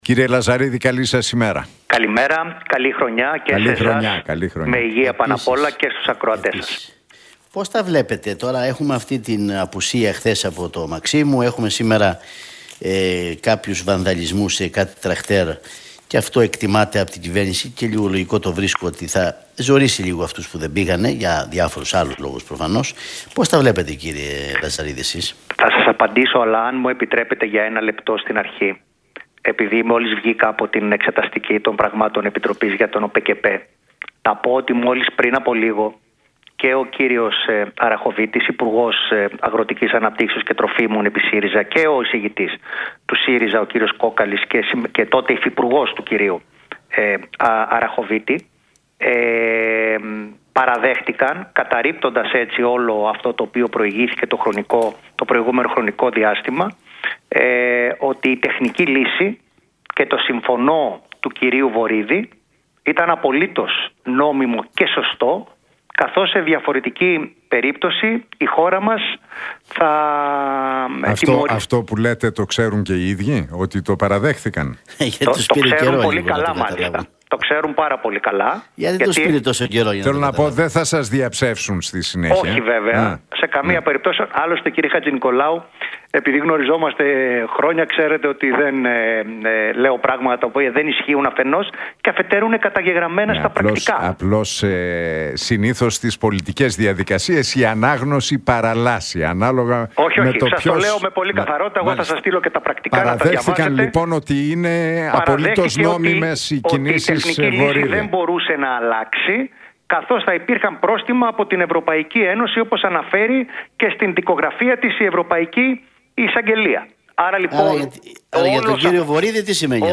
Για τον ΟΠΕΚΕΠΕ, τους αγρότες, αλλά και τον υπό ίδρυση πολιτικό φορέα της Μαρίας Καρυστιανού, μίλησε ο κοινοβουλευτικός εκπρόσωπος της ΝΔ Μακάριος Λαζαρίδης στην εκπομπή του Νίκου Χατζηνικολάου